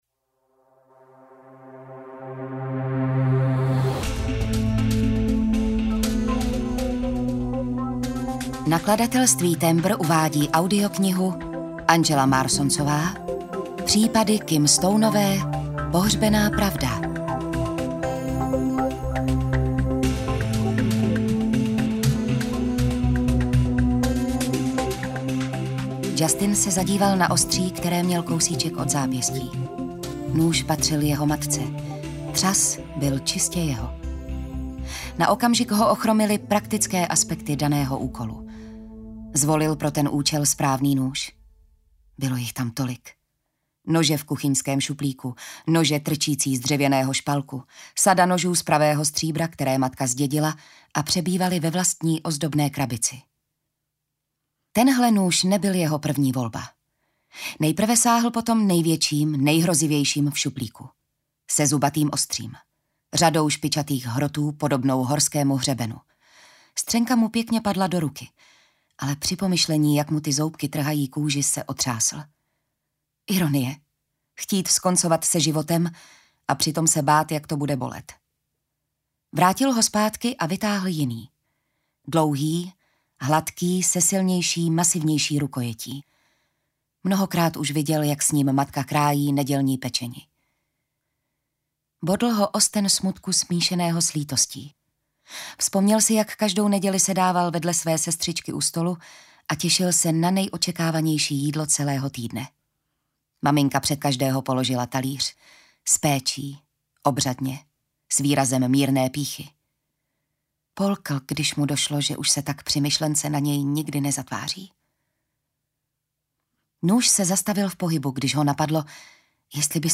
Pohřbená pravda audiokniha
Ukázka z knihy
• InterpretJitka Ježková